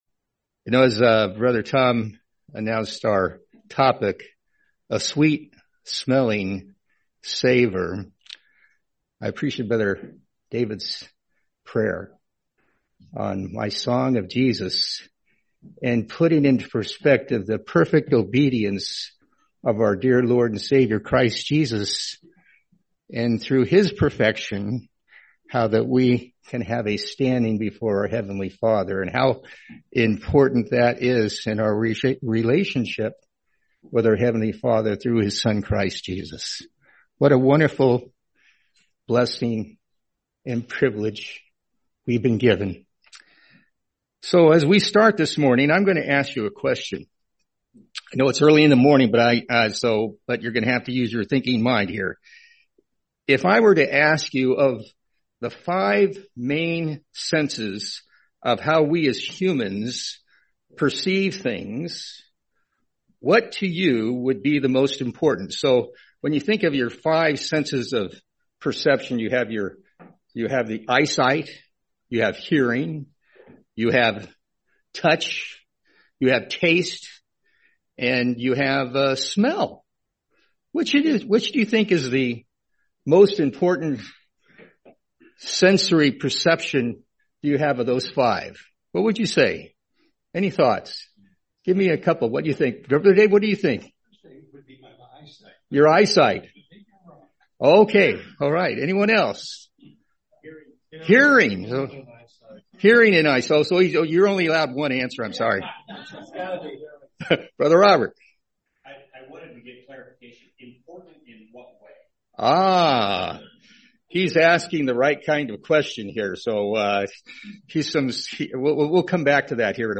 Series: 2025 Sacramento Convention